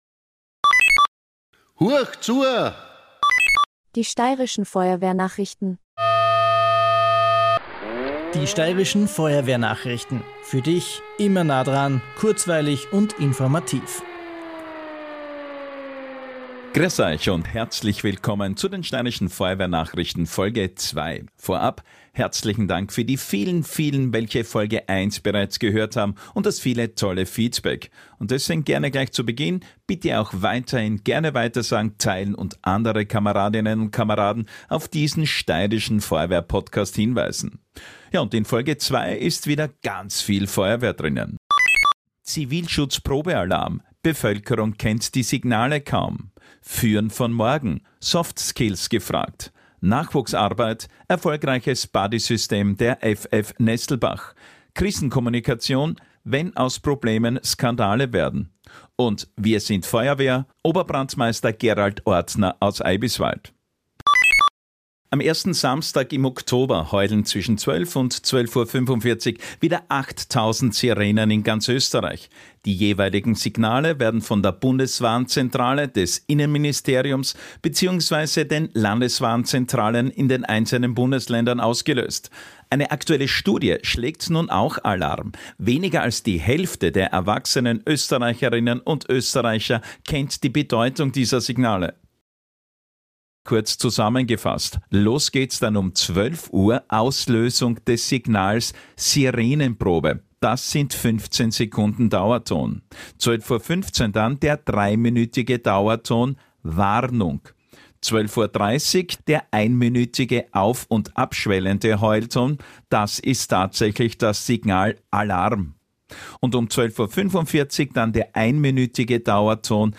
Genau dazu laden wir euch ein: Feuerwehr-Themen aus der Steiermark und für die Steiermark – informativ, kompakt und im Stil einer Nachrichtensendung.